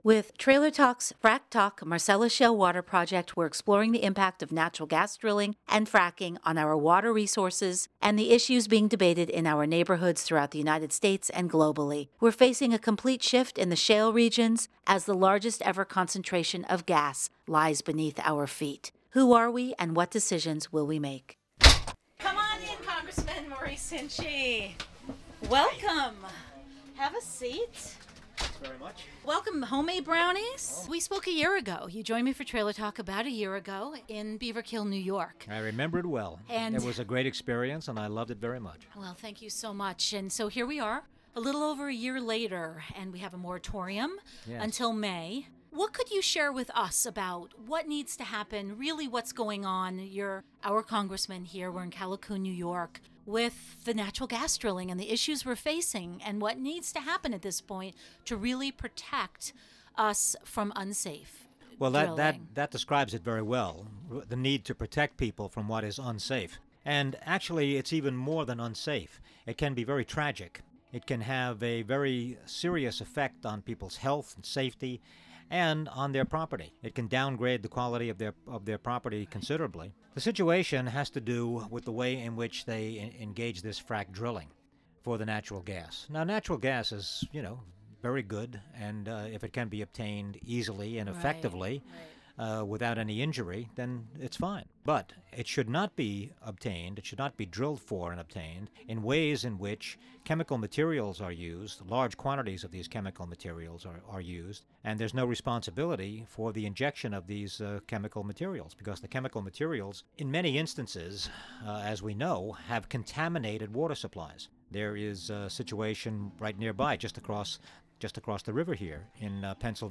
The event was at the Delaware Youth Center in Callicoon, NY in the Sullivan County Catskills.